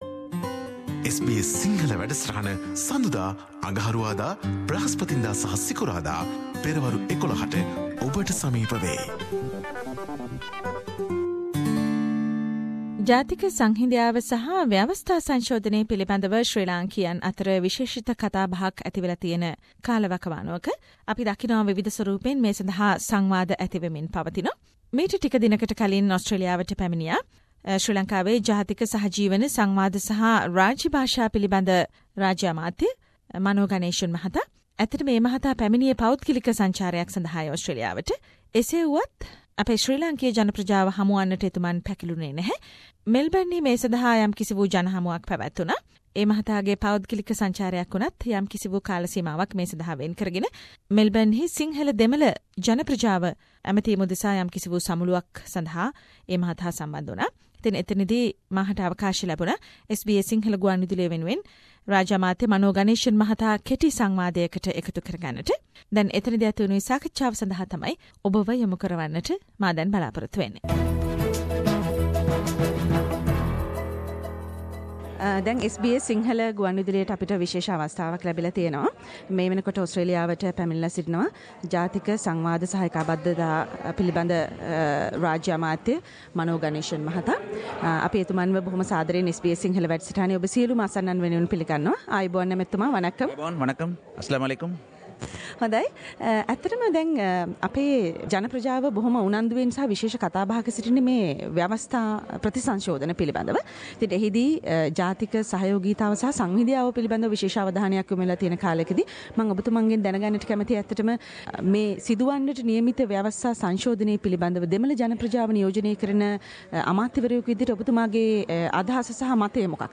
පිටරටවලදී 'ෆෙඩරල්' වන ලංකාවේදී 'ඒකීය' වන ලාංකිකයින් එක්ක සංහිඳියා ක්‍රියාදාමය ගෙනයෑම අපහසුයි - රාජ්‍ය අමාත්‍ය මනෝ ගනේෂන් SBS සිංහල ට කියූ කතාව